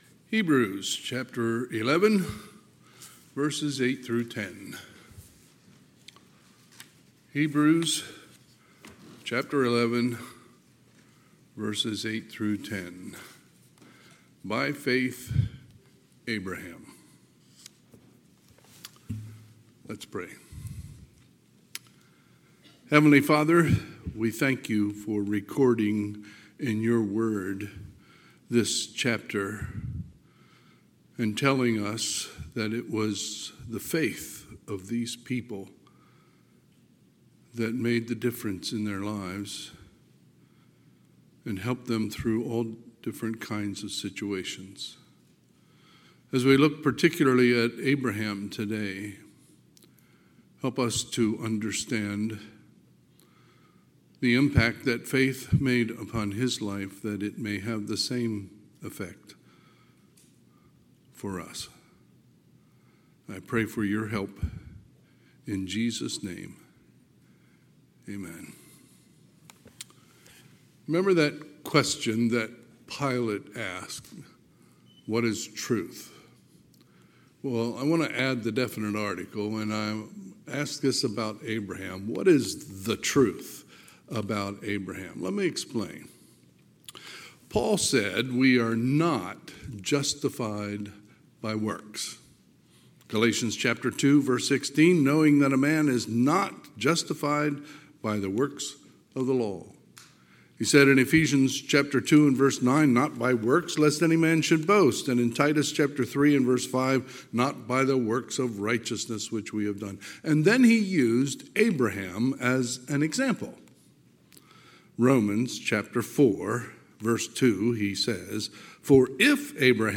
Sunday, September 15, 2024 – Sunday AM
Sermons